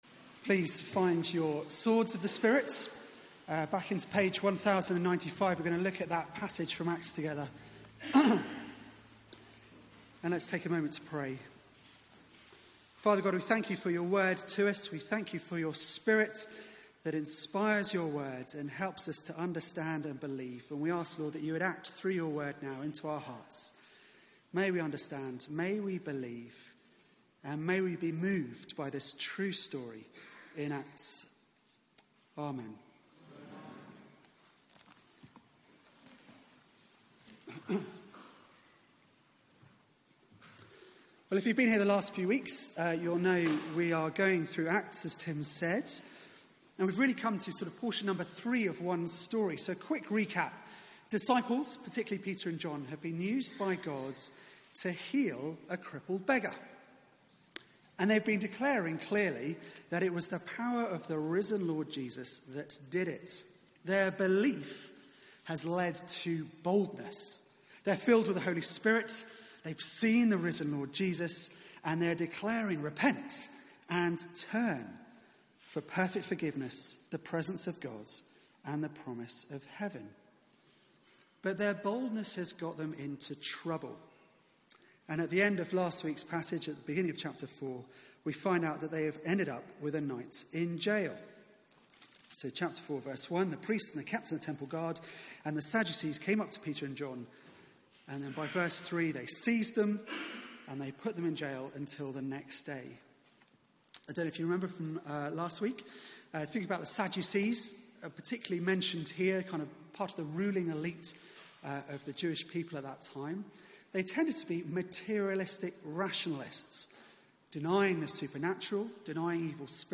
Media for 6:30pm Service on Sun 29th Jul 2018 18:30 Speaker